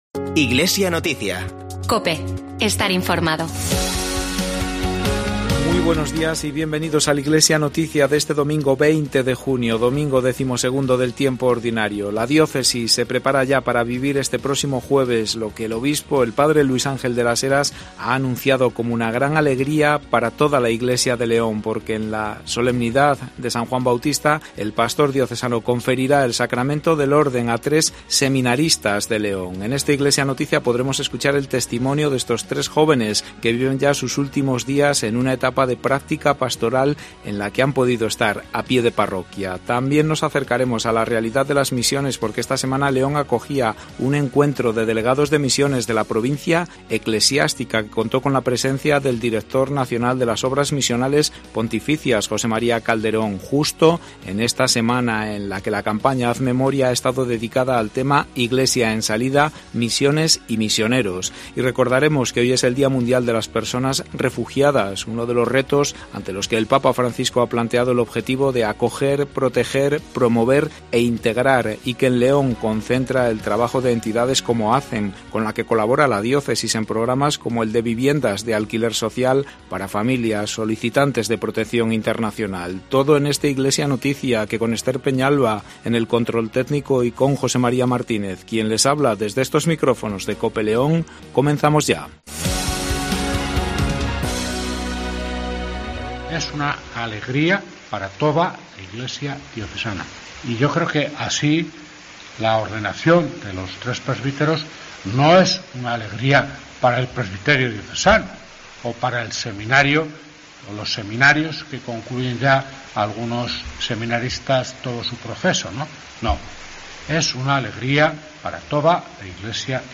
INFORMATIVO DIOCESANO